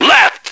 Index of /action/sound/radio/male